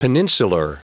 Prononciation du mot peninsular en anglais (fichier audio)
Prononciation du mot : peninsular
peninsular.wav